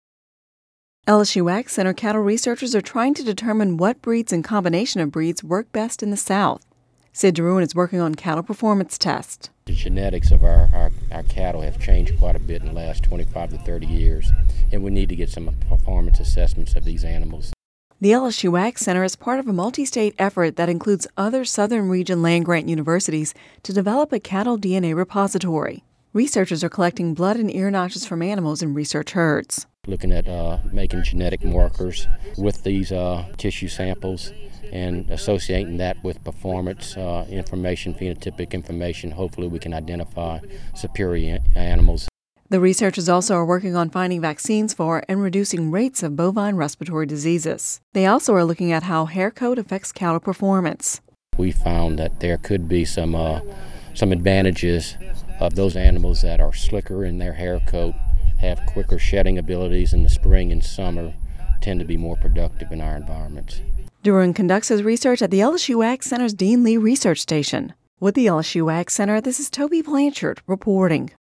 (Radio News 09/06/10) LSU AgCenter cattle researchers are trying to determine what breeds and combinations of breeds work best in the South.